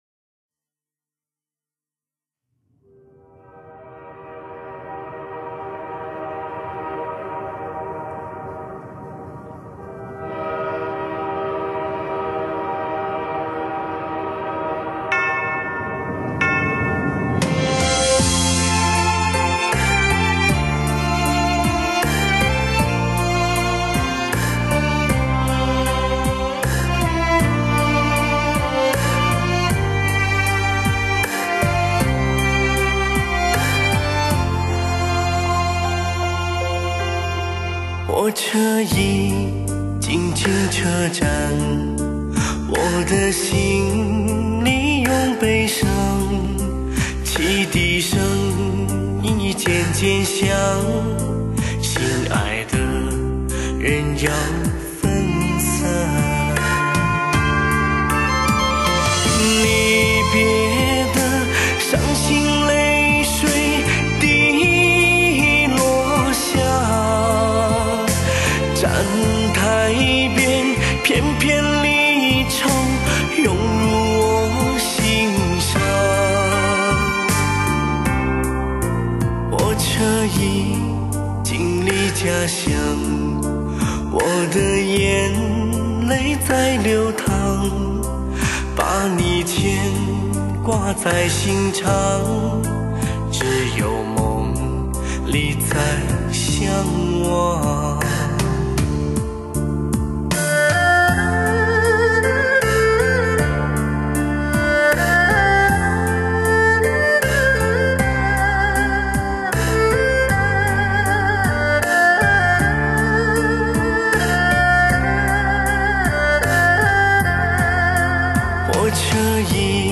环绕Hi-Fi AUTO SOUND专业天碟，专有STS Magix Mastering母带制作，
STS Magix Virtual Live高临场感CD！